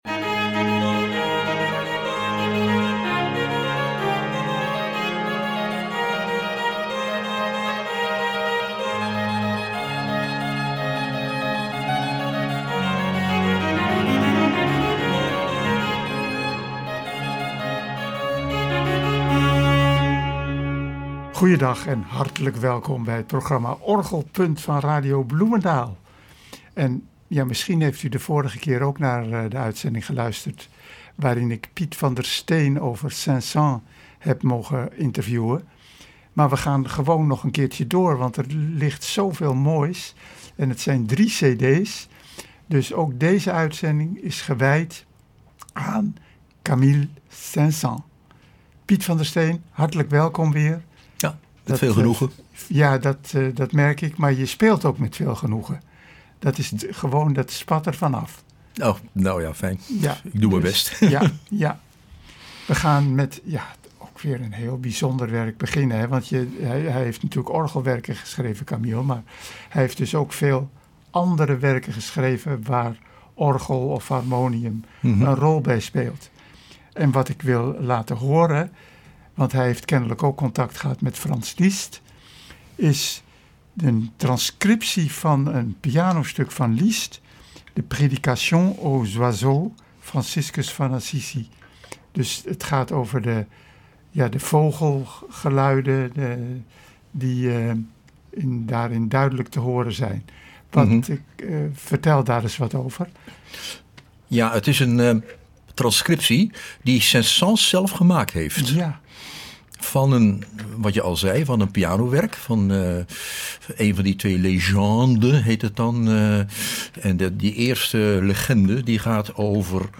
Hij speelt de muziek van de cd’s op een mooi Cavaillé-Coll orgel.
Ook daar is muziek van te horen.